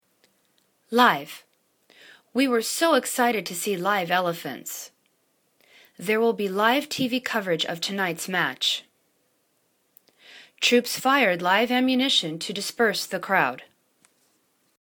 live    /li:v/    adj